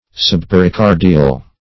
Search Result for " subpericardial" : The Collaborative International Dictionary of English v.0.48: Subpericardial \Sub*per`i*car"di*al\, a. (Anat.)
subpericardial.mp3